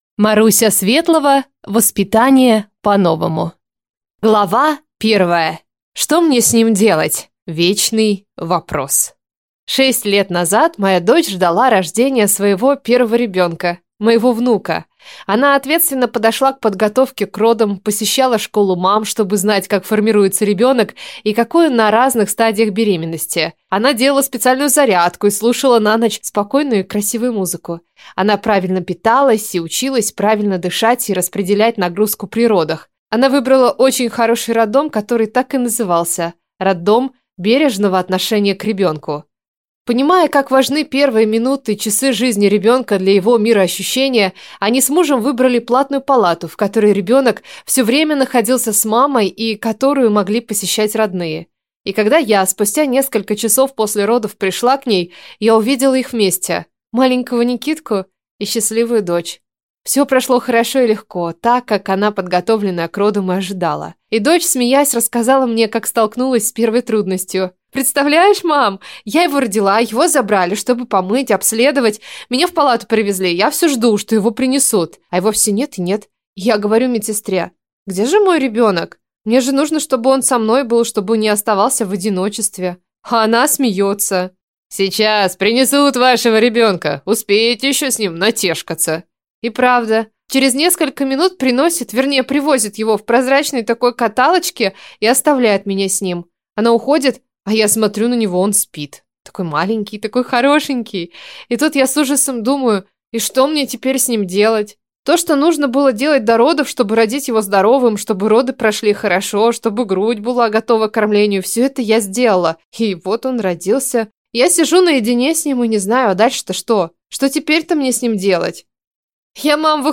Аудиокнига Воспитание по-новому | Библиотека аудиокниг